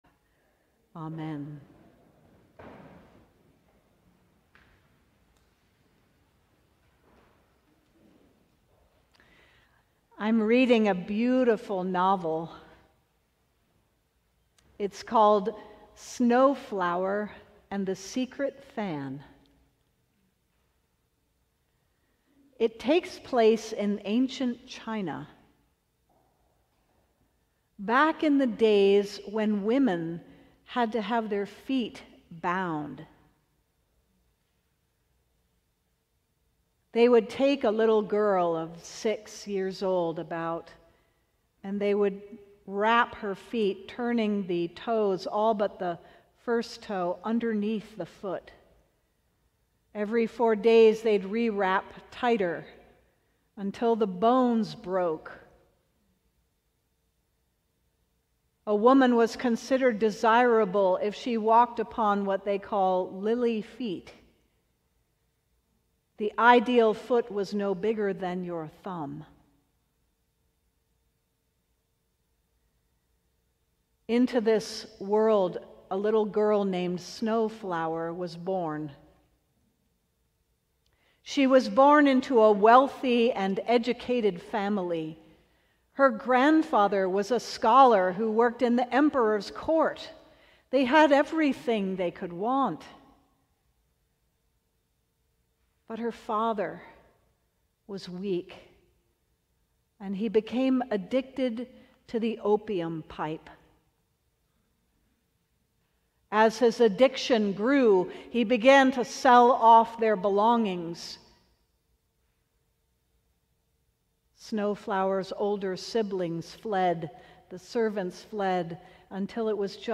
Sermon: Standing in the Gap